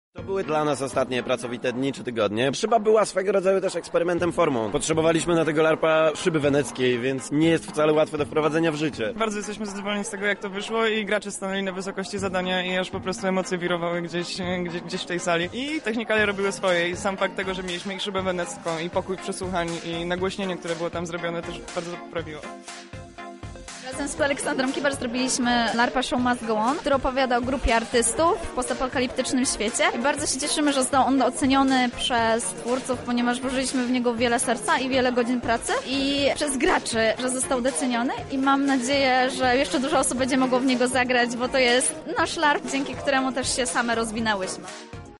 LubLarp relacja